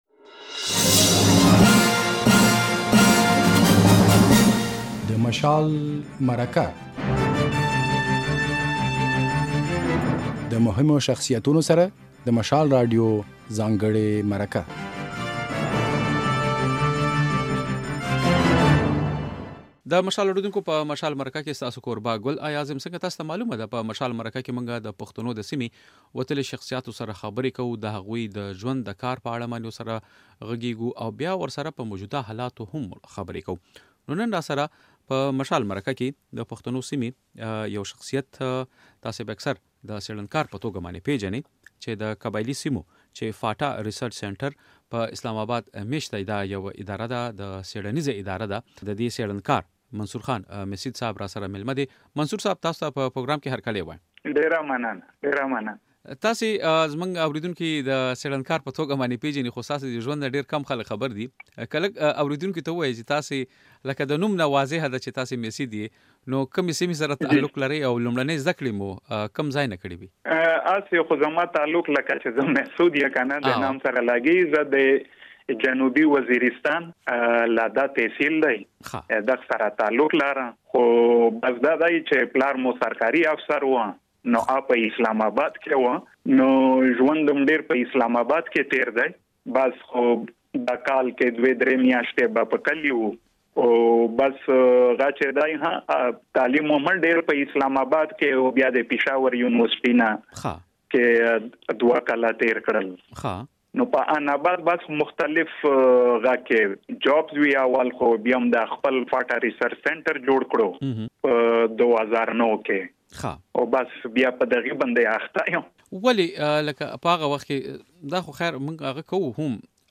د نوموړي په وینا، په قبايلي ضلعو کې د ناامنۍ ترشا وجوهات يوه سابقه لري. هغه زیاته کړه چې پښتانه بايد د سياسي نعرو پرځای د خپلو ګټو او زیانونو په اړه فکر وکړي. بشپړه مرکه واورئ.